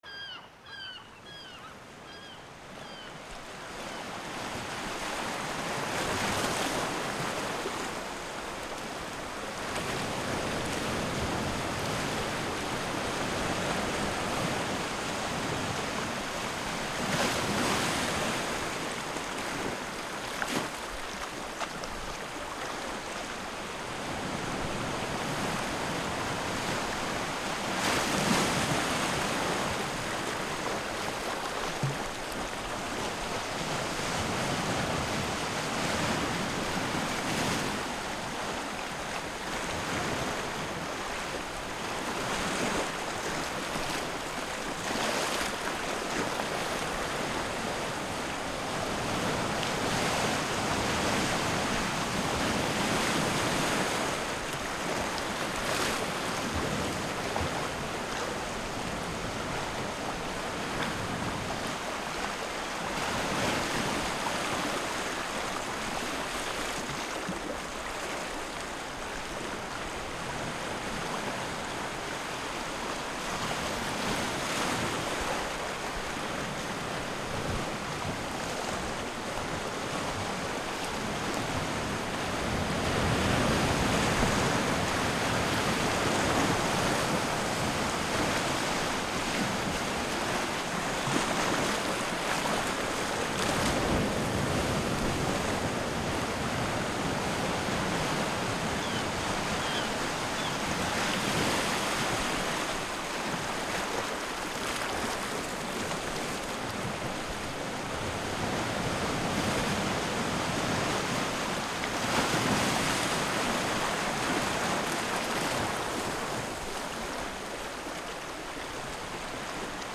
Звук Моря - Дыхание моря